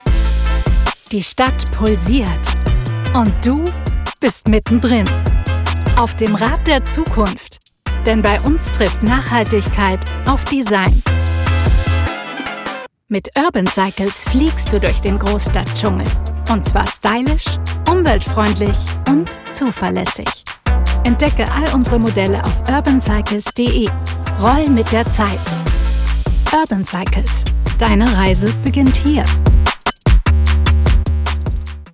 Werbung – Urban Cycles
mitteltief ⋅ frisch ⋅ facettenreich